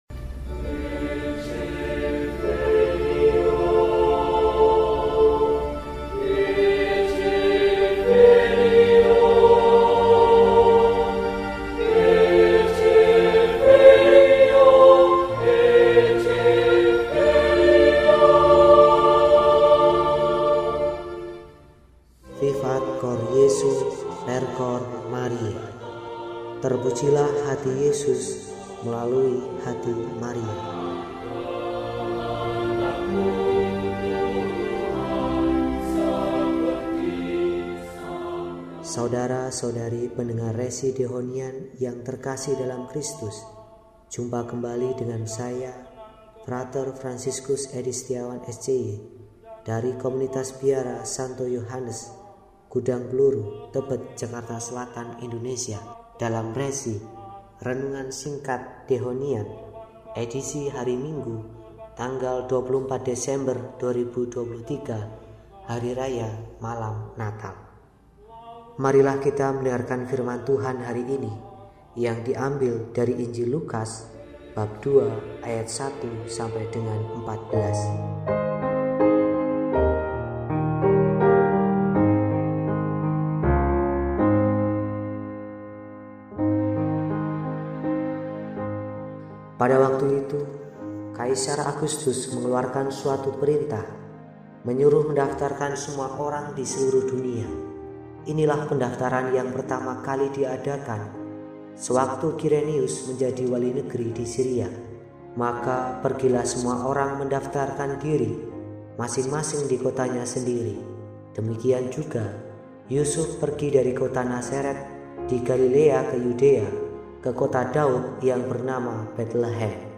Minggu, 24 Desember 2023 – Hari Raya Natal -Malam Natal – RESI (Renungan Singkat) DEHONIAN